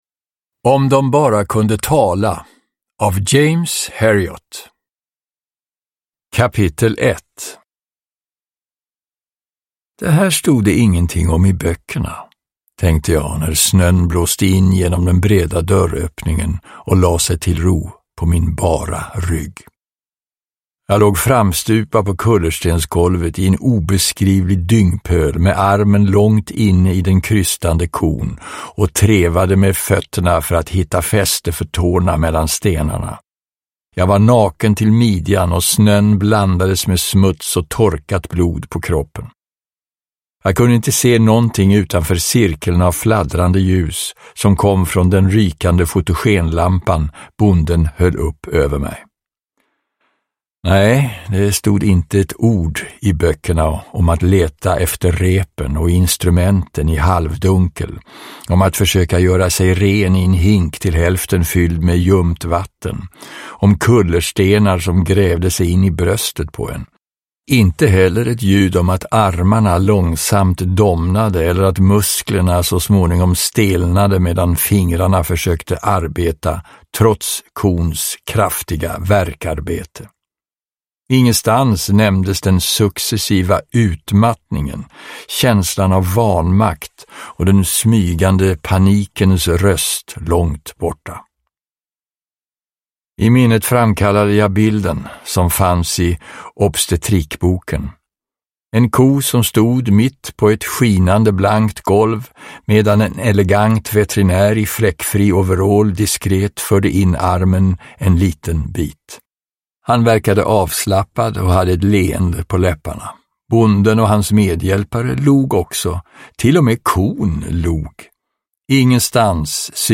Om dom bara kunde tala – Ljudbok – Laddas ner
Uppläsare: Björn Granath